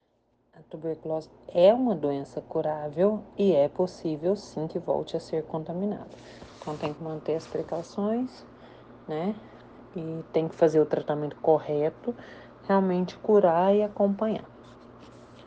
Especialista explica como se prevenir e tratar da doença que é considerada um problema de saúde pública